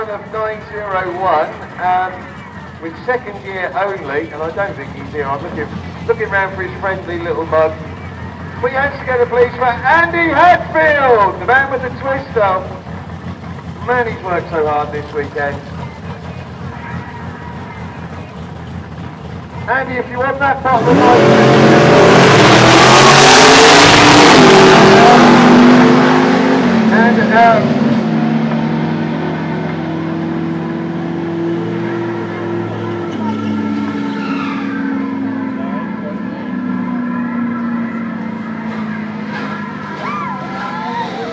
A 10-second run at Santa Pod